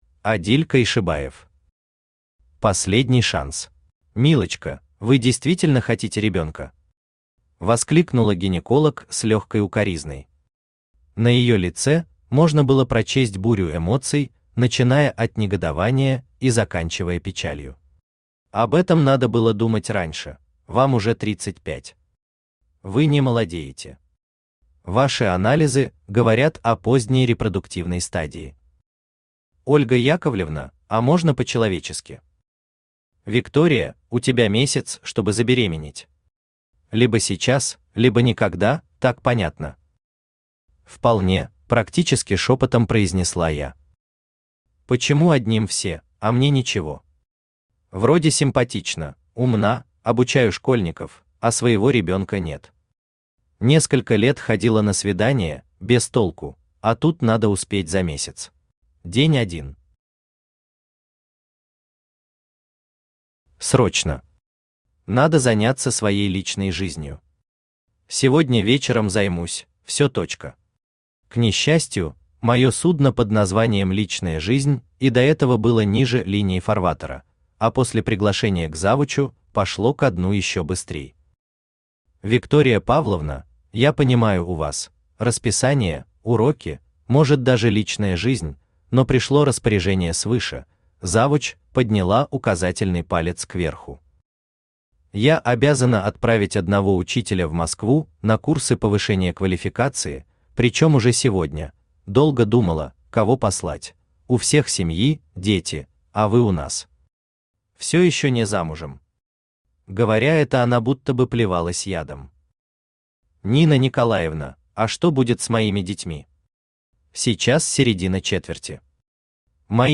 Аудиокнига Последний шанс | Библиотека аудиокниг
Aудиокнига Последний шанс Автор Адиль Койшибаев Читает аудиокнигу Авточтец ЛитРес.